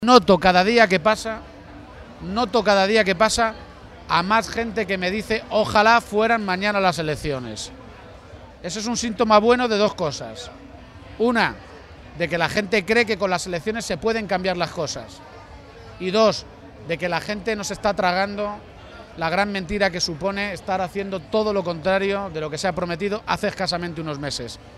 Emiliano García-Page, Secretario General del PSCM-PSOE
Respondía así García-Page a preguntas de los medios de comunicación, durante su visita a la Feria de Talavera de la Reina, sobre la posibilidad de acordar las cuentas regionales de este año, que aún no están presentadas a pesar de estar ya a mediados del mes de mayo.